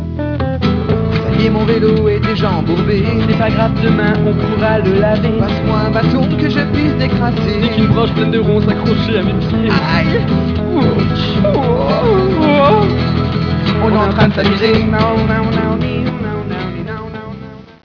Les chanteurs : En deux groupes A ou B.
L’introduction parlée :